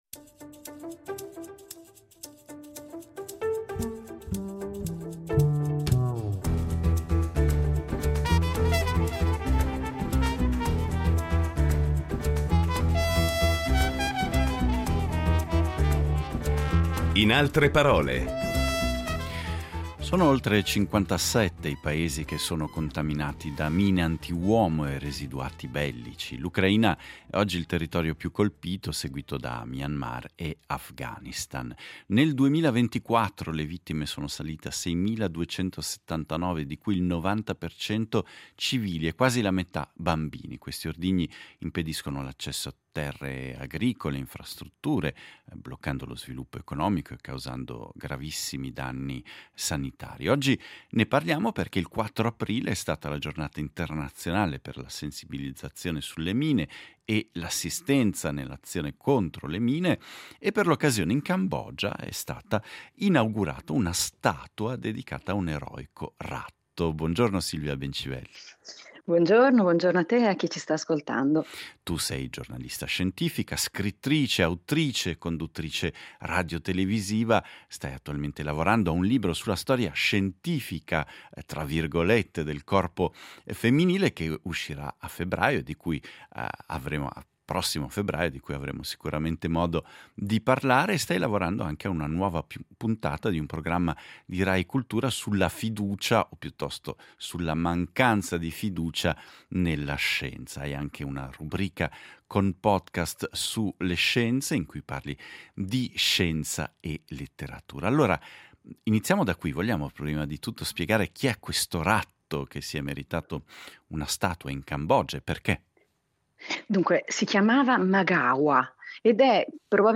Incontro con la giornalista scientifica, scrittrice, autrice e conduttrice radiotelevisiva